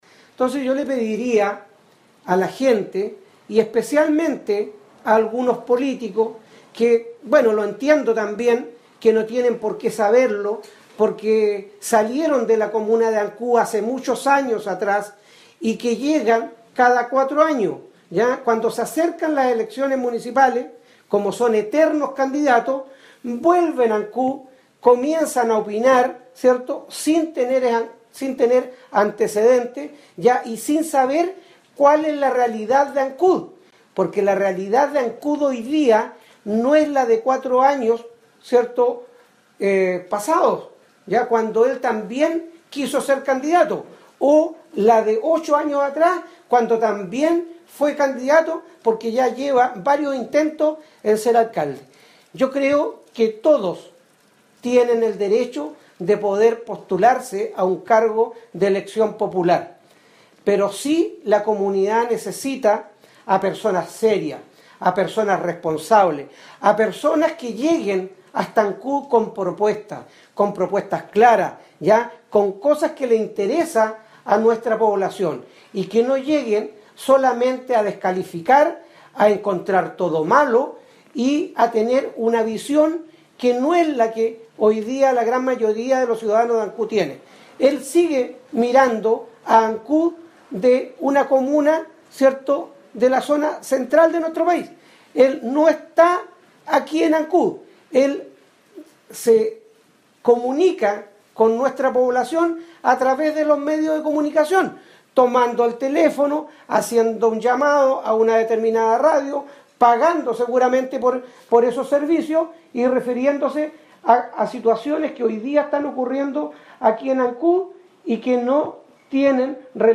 Esto fue lo señalado por la autoridad comunal de Ancud.